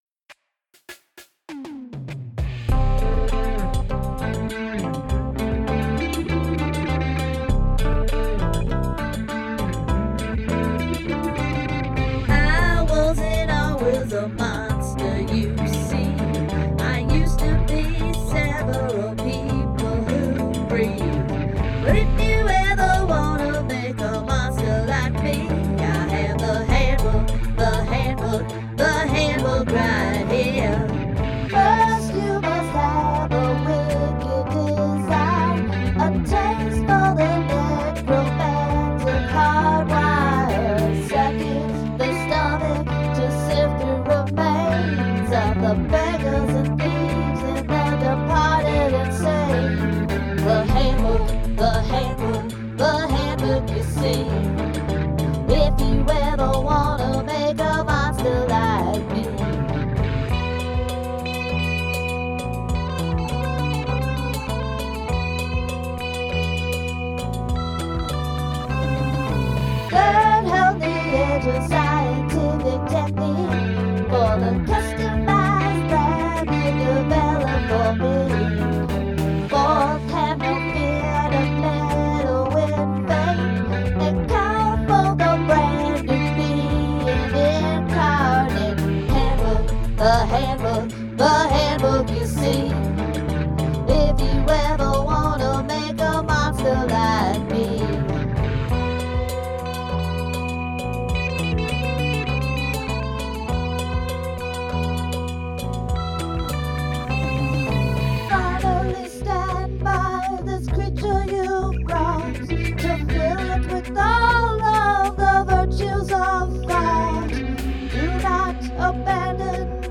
The swell of sounds In the bridge is my favorite part.